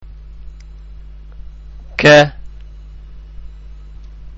ഉച്ചാരണം (പ്ലേ ബട്ടണ്‍ അമര്‍ത്തുക)